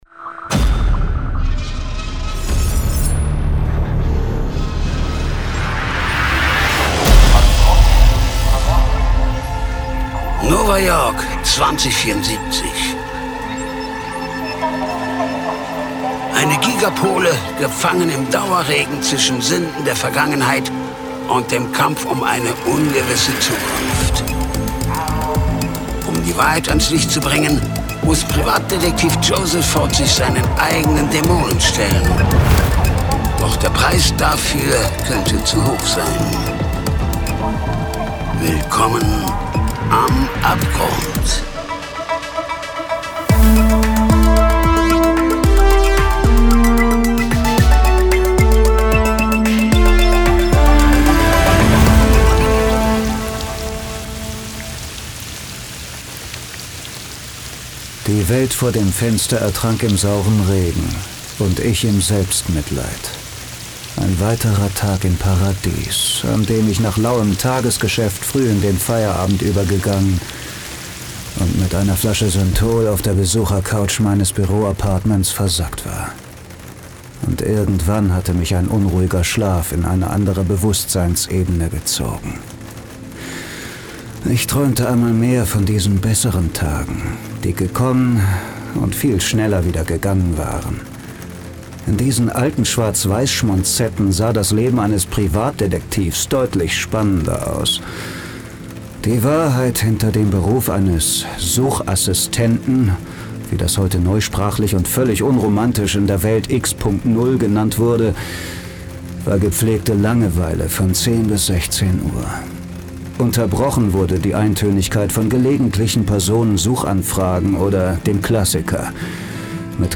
Die SciFi-Noir-Krimi-Hörspielserie Podcast